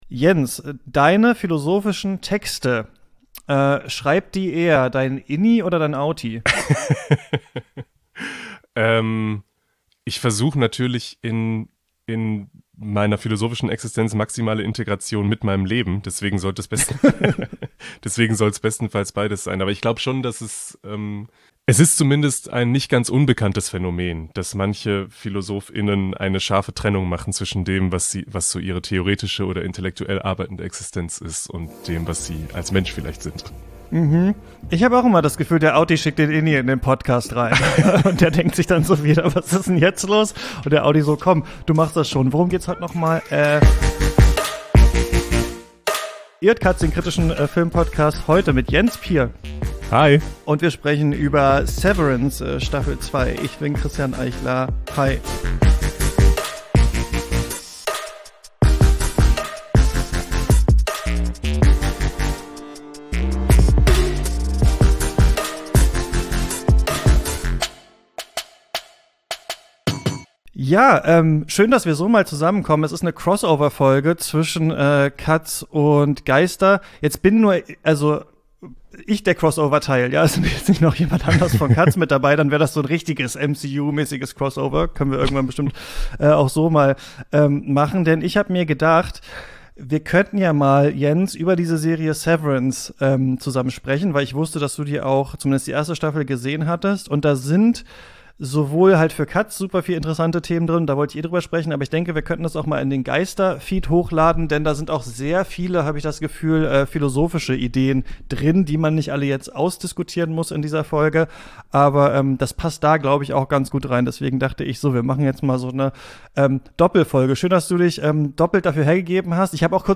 Gegen Ende des Jahres haben wir noch eine letzte Festivalfolge für euch. Zum ersten Mal senden wir vom Internationalen Filmfestival Mannheim Heidelberg.